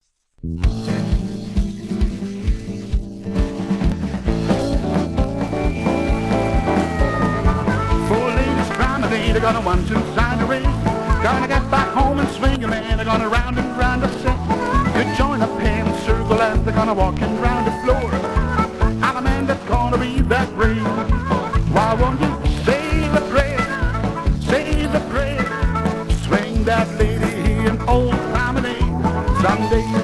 Instrumental
Vocal